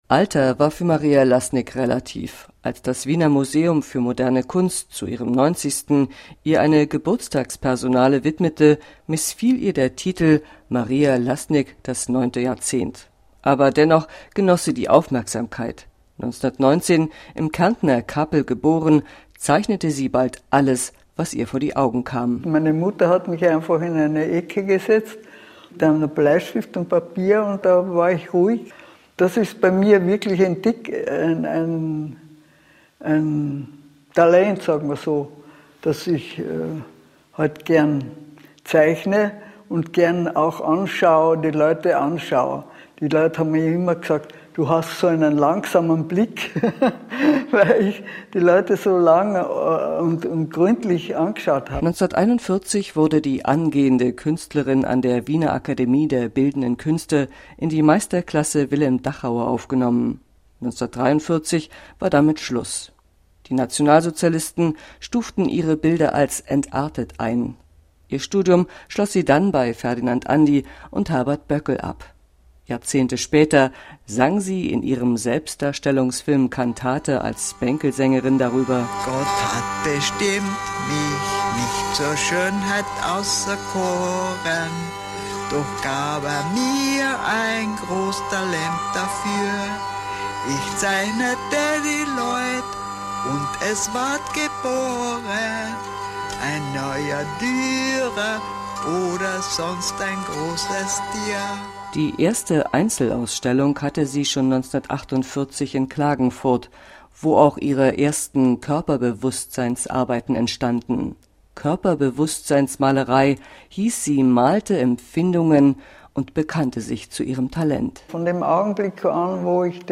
Ein Nachruf zu Maria Lassnigs Tod